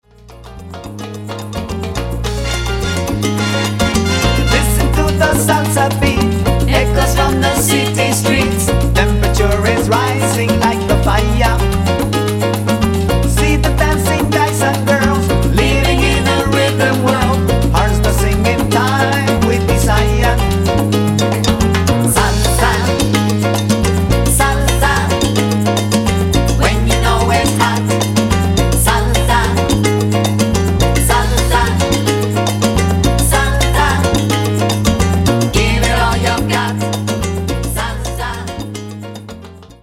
Sizzling Latin sounds